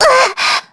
Maria_L-Vox_Damage_kr_02.wav